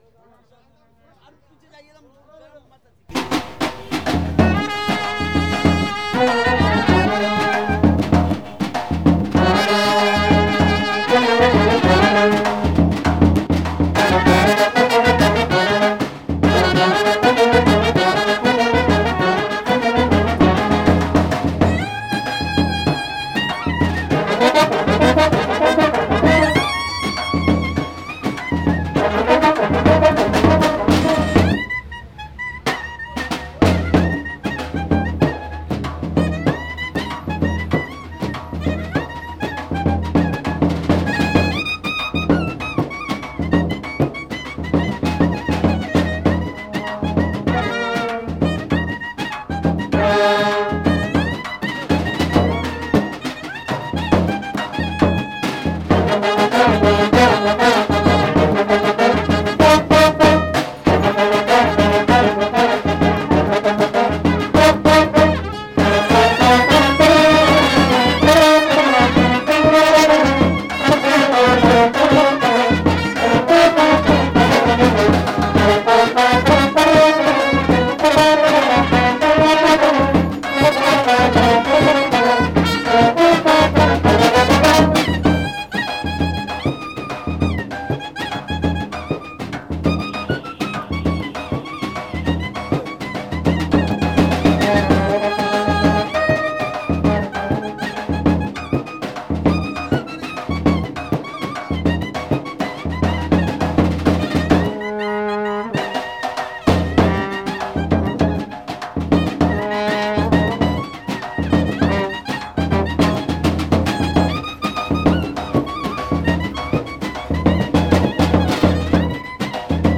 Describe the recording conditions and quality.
A brass band in Kolkata’s Mahatma Gandhi Road, recorded in November 1996